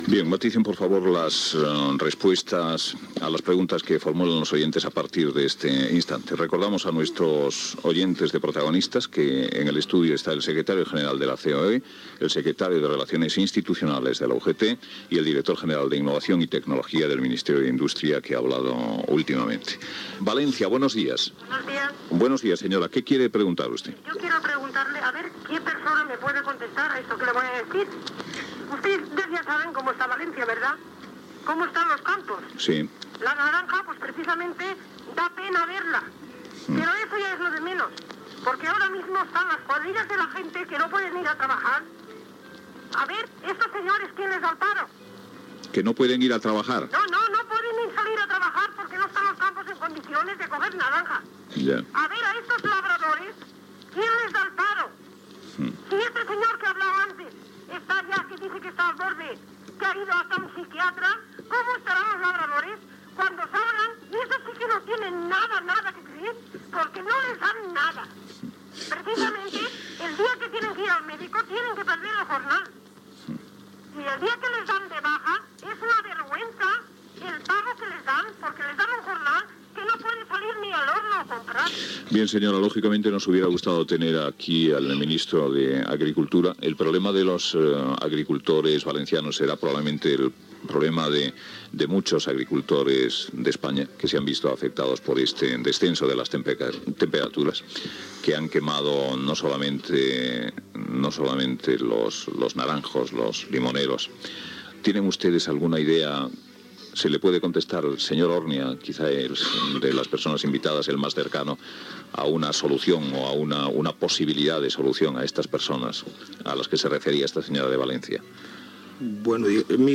Testimoni d'un aturat.
Persones presents a l'estudi i trucades telefòniques.